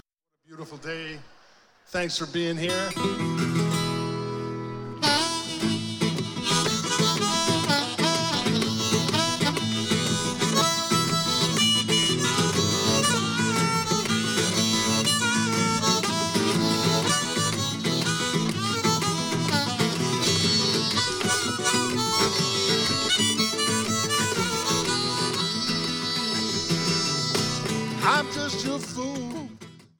Acoustic
Blues